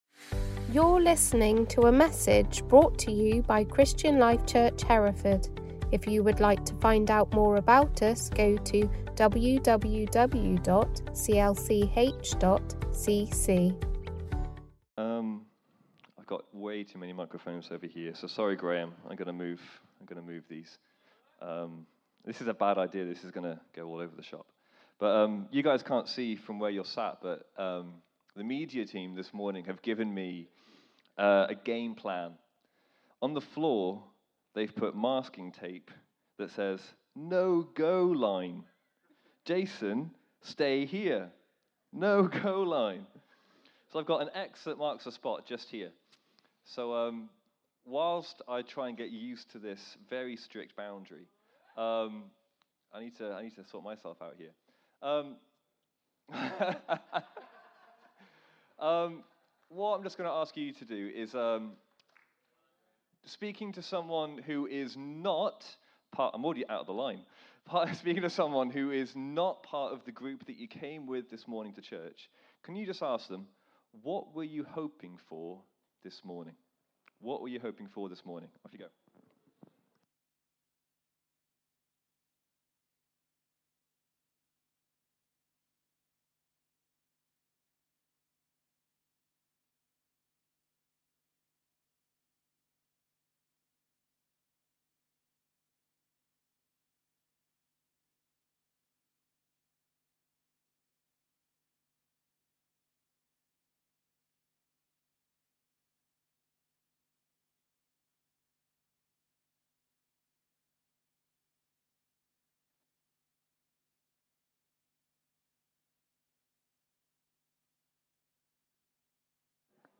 Location: Leominster Sunday